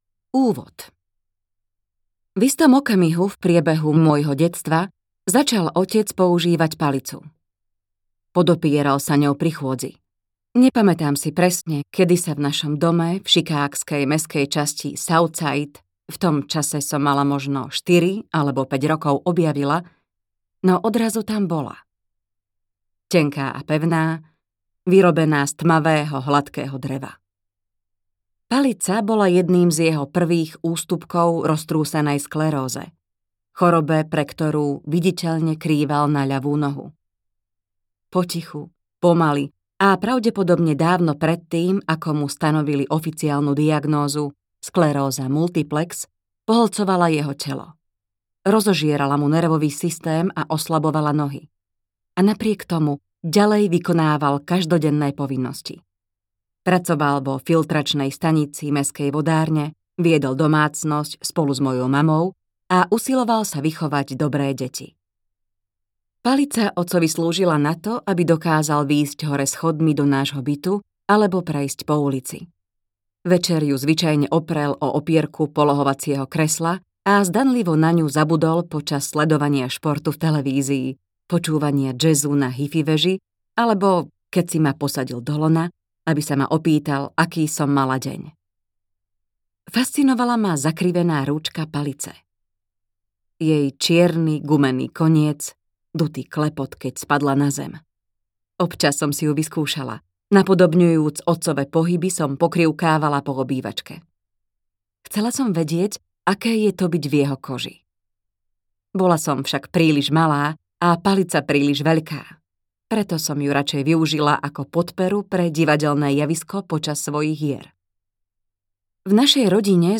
Audiokniha Svetlo v nás - Michelle Obama | ProgresGuru